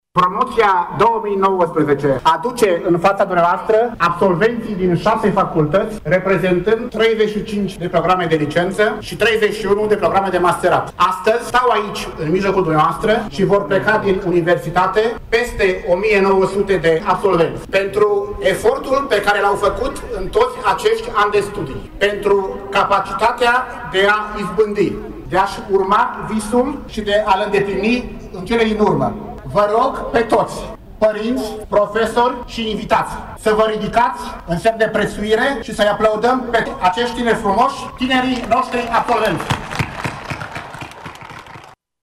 Peste 1900 de absolvenți au fost prezenți astăzi pe stadionul Municipal pentru a fi celebrați de părinți, cadrele dactice și de invitații speciali ai evenimentului.